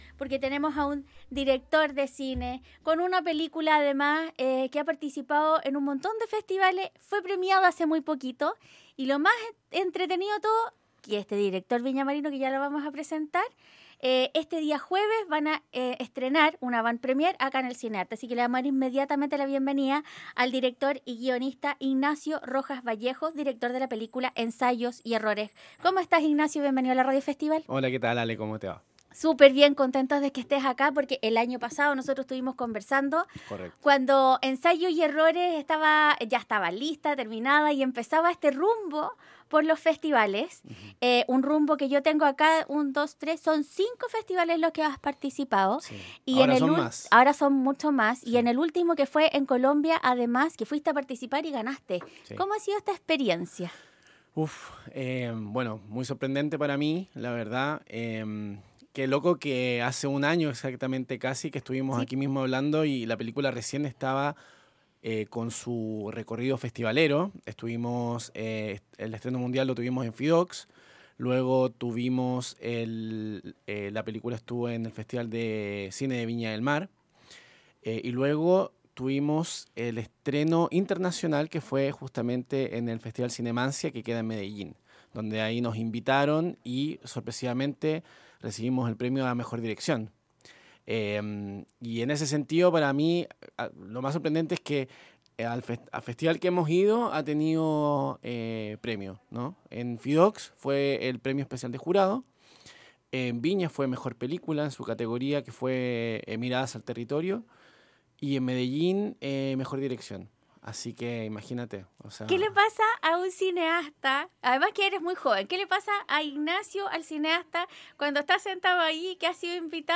Director Viñamarino en los estudios de Radio Festival